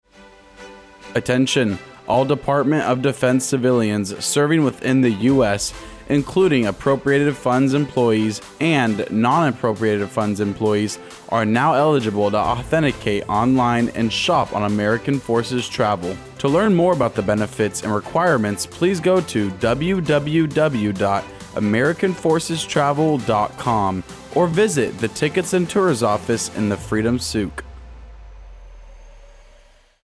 Radio SpotMWR Bahrain